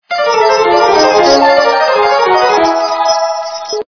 » Звуки » звуки для СМС » Звук - СМС
При прослушивании Звук - СМС качество понижено и присутствуют гудки.